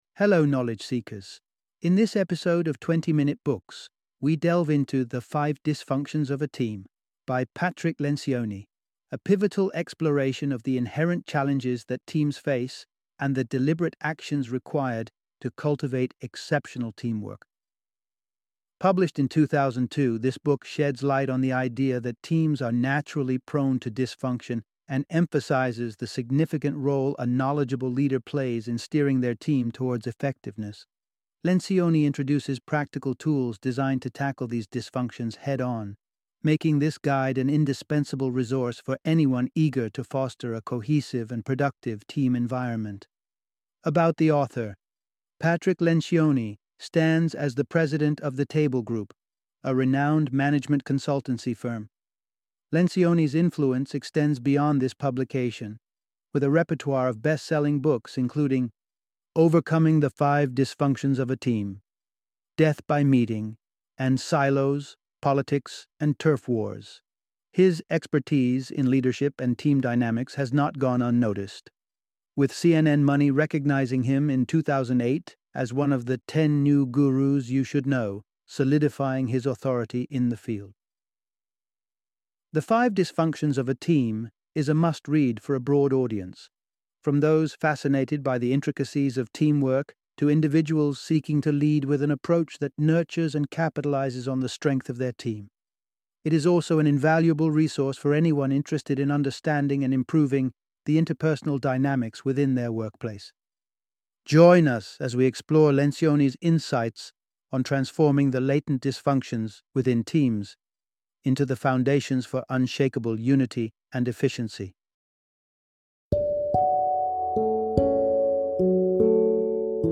The Five Dysfunctions of a Team - Audiobook Summary